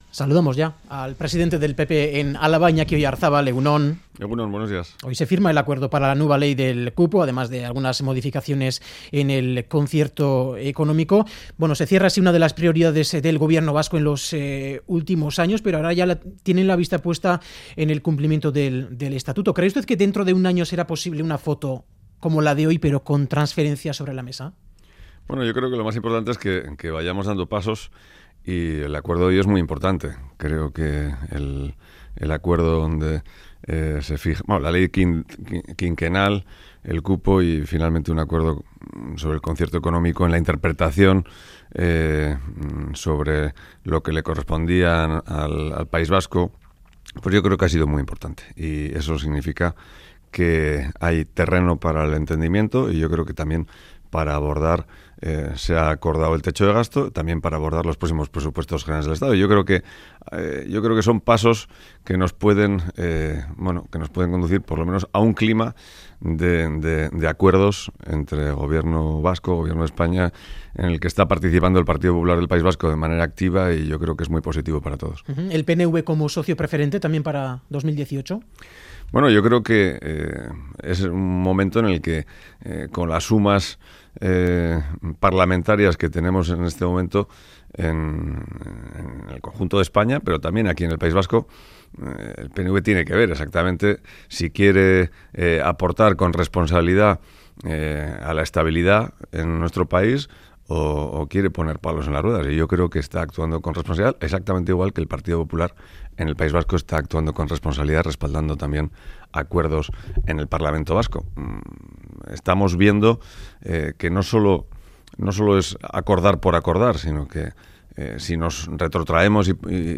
Audio: Entrevista a Iñaki Oyarzabal presidente del Partido Popular de Álava